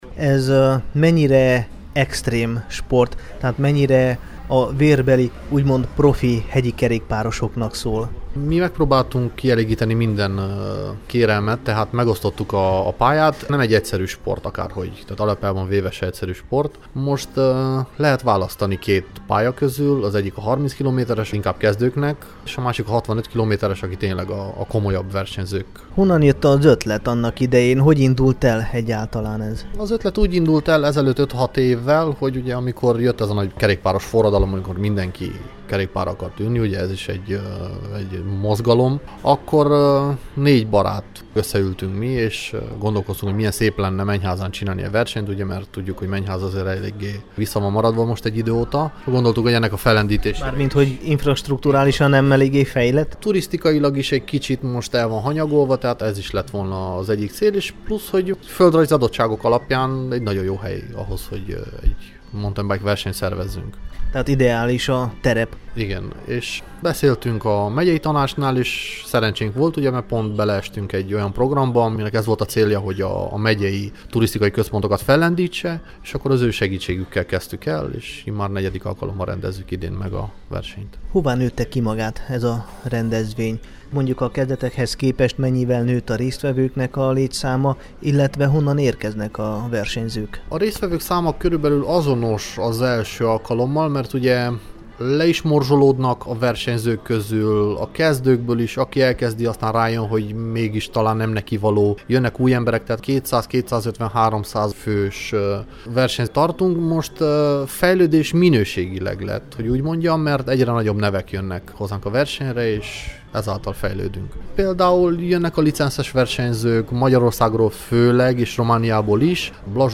beszélt a Temesvári Rádió ifjúsági műsorában